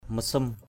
/mə-srum/